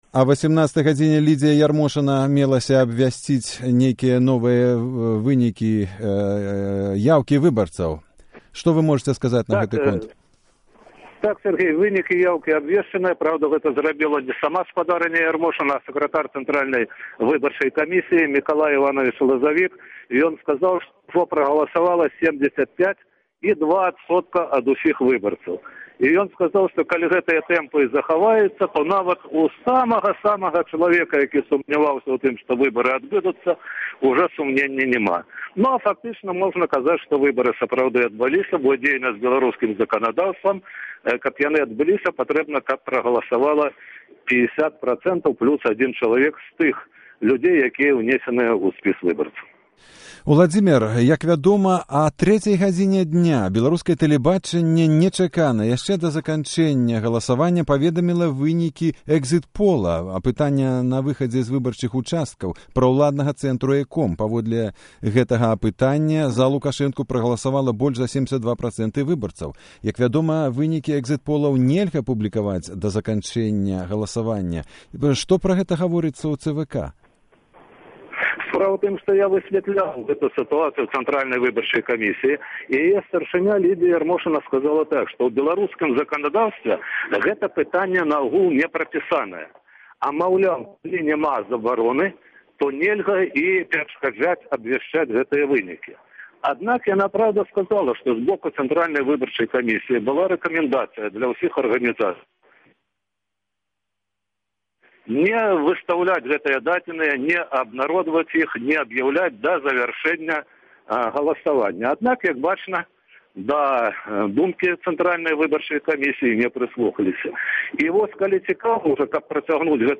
Рэпартаж з ЦВК